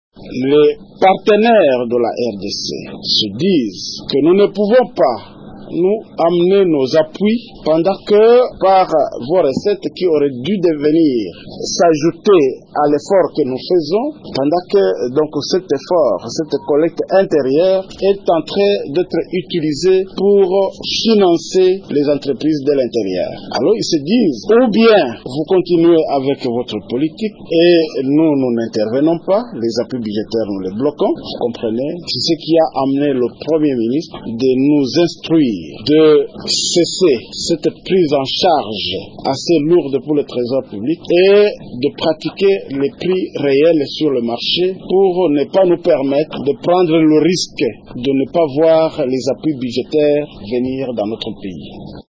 Le ministre Bulambo Kilosho précisé: